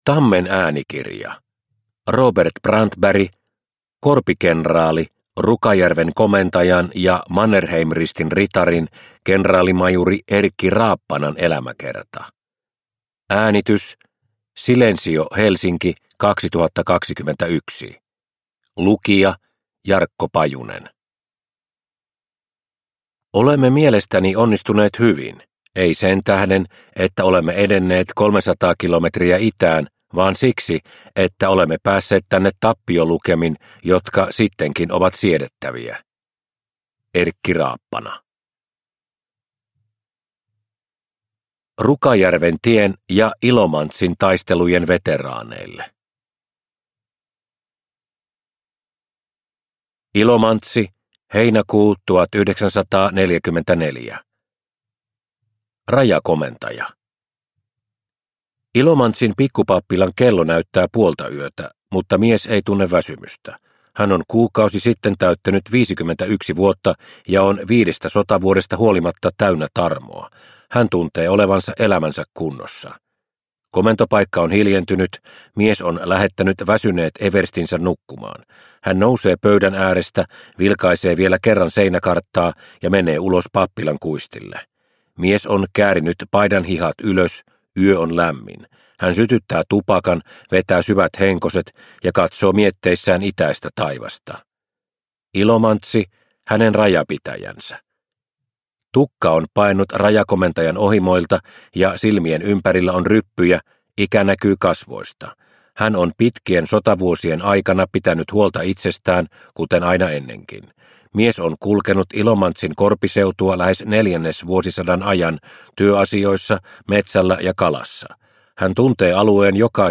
Korpikenraali – Ljudbok – Laddas ner